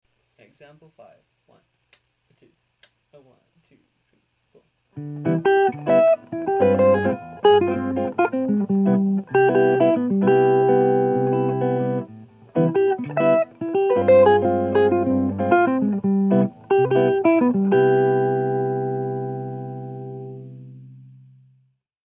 For ease of understanding, I've written all the examples as Major II-V-I progressions in the key of C Major.
(Listen) - Here I start off with a linear open voiced triad phrase followed by the use of a four over three polyrhythmic grouping to create forward motion in the phrase.